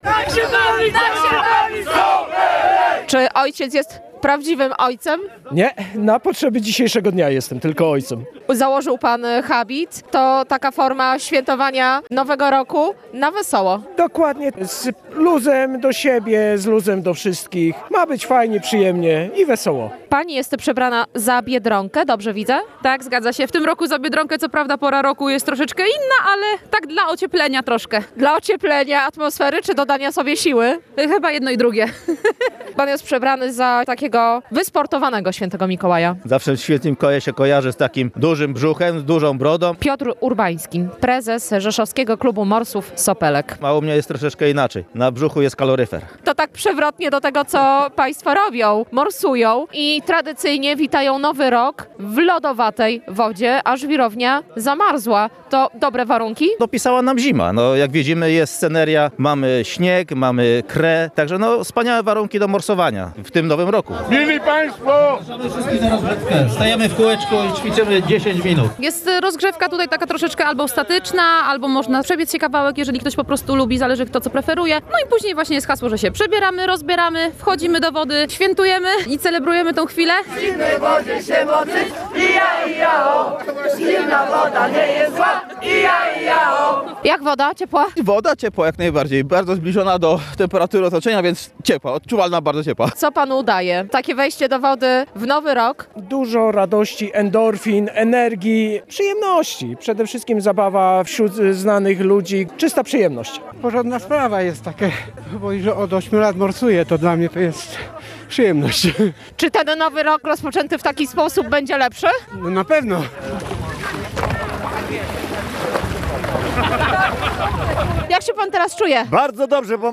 morsowali-w-Nowy-Rok-na-Zwirowni.mp3